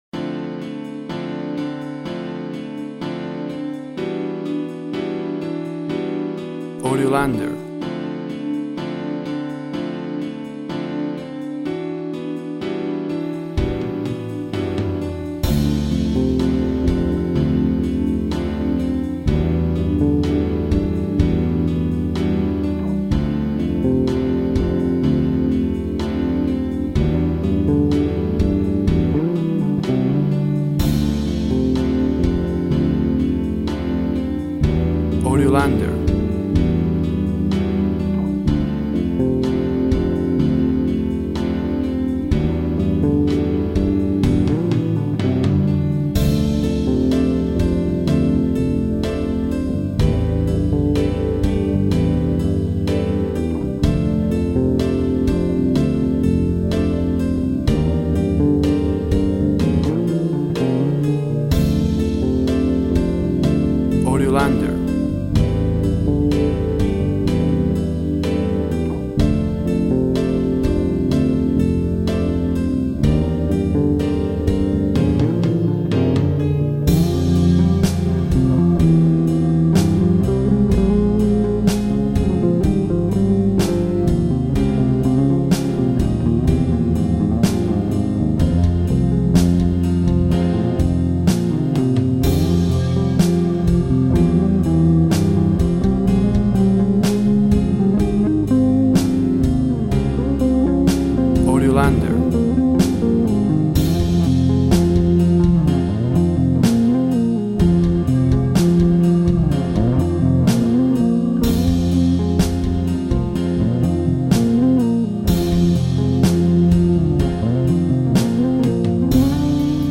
Instrumental bass minus vocal.
Tempo (BPM) 80